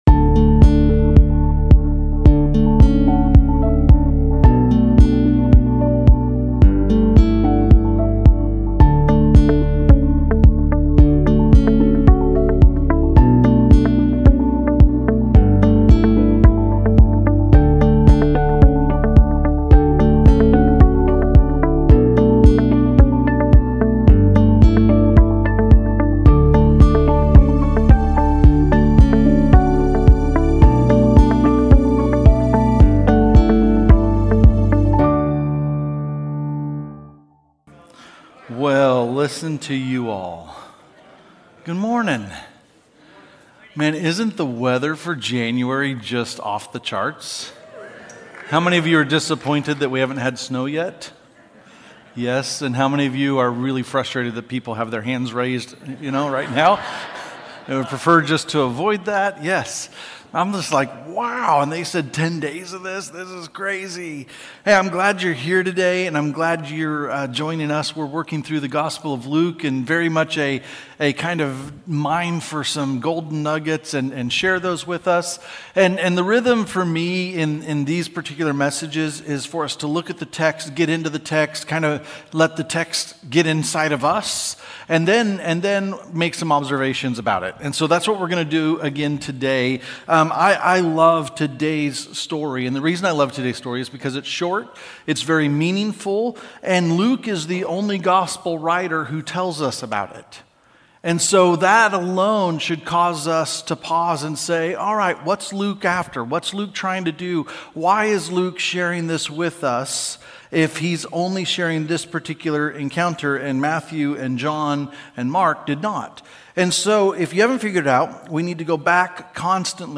Sermons | Mountainview Christian Church